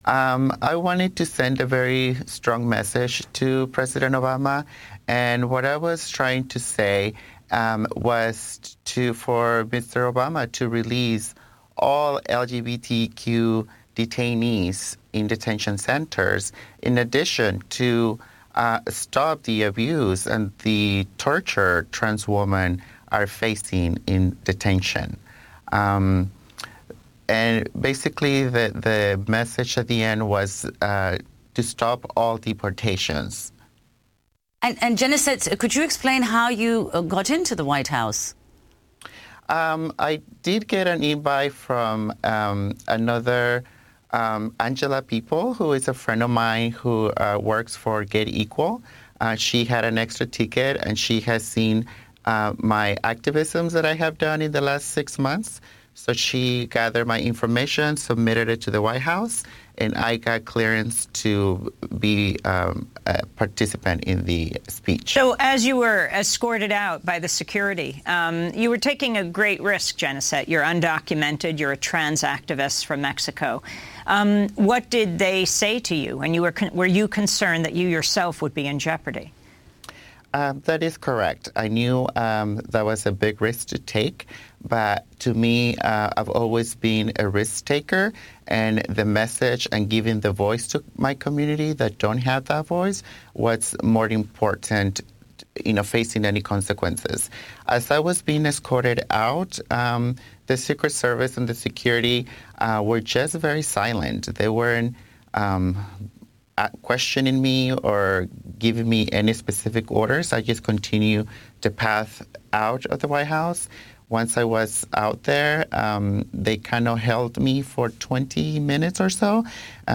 Progressive talk radio from a grassroots perspective
Hosted by: Jo Ann Hardesty Produced by: KBOO Subscribe to podcast Every Thursday from 8:00 am - 9:00 am Progressive talk radio from a grassroots perspective About the program …